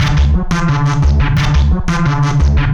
Too Bad Loop 5.WAV